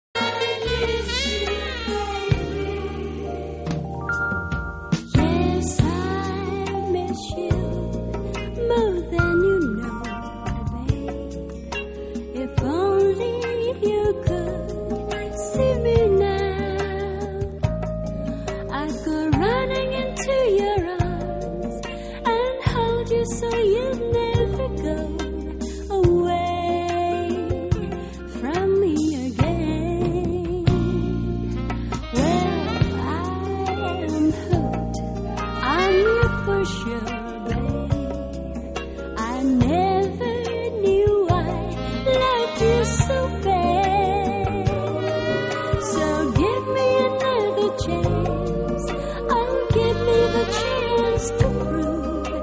elle chante bien non ?